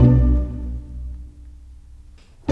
Rock-Pop 09 Pizzicato 09.wav